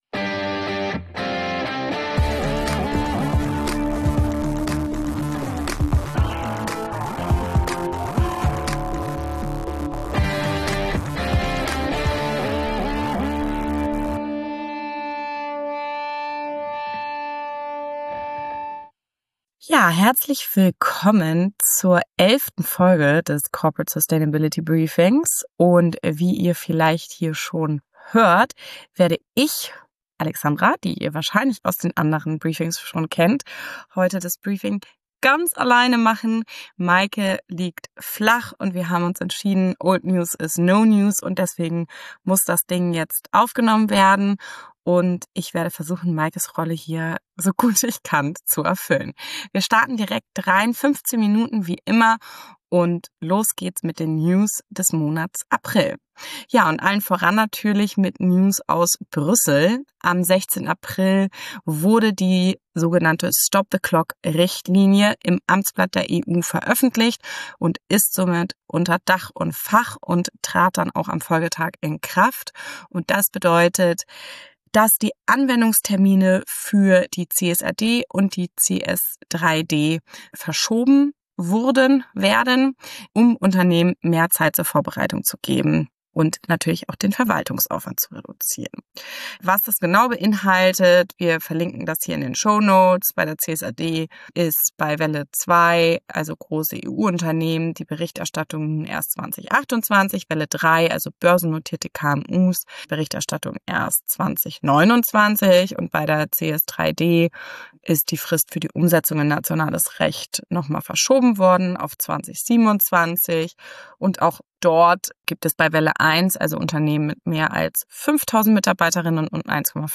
CEO2-neutral - Der Interview-Podcast für mehr Nachhaltigkeit im Unternehmen